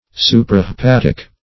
Search Result for " suprahepatic" : The Collaborative International Dictionary of English v.0.48: Suprahepatic \Su`pra*he*pat"ic\, a. (Anat.)
suprahepatic.mp3